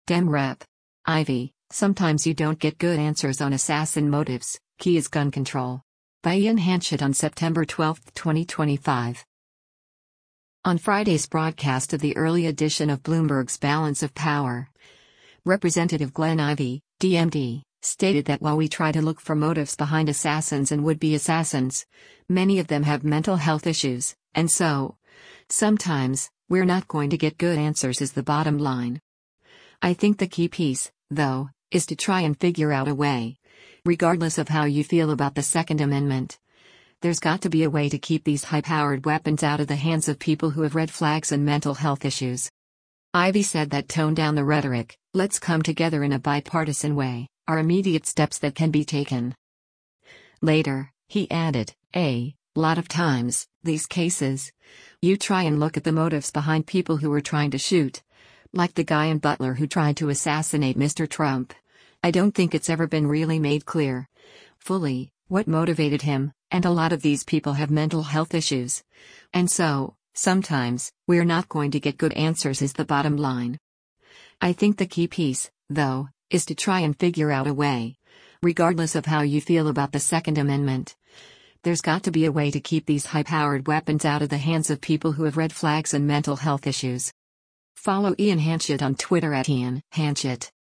On Friday’s broadcast of the early edition of Bloomberg’s “Balance of Power,” Rep. Glenn Ivey (D-MD) stated that while we try to look for motives behind assassins and would-be assassins, many of them “have mental health issues, and so, sometimes, we’re not going to get good answers is the bottom line.